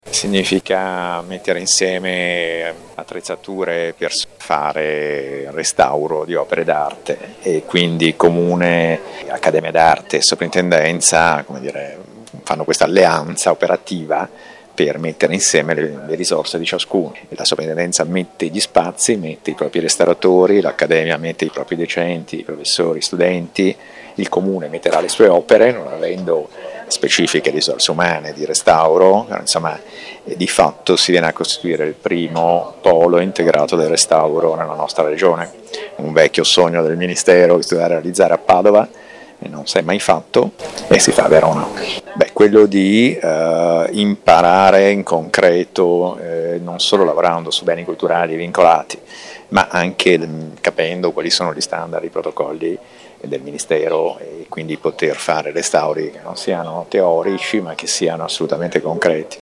Ai nostri microfoni si sono espressi alcuni dei responsabili di questo grande risultato: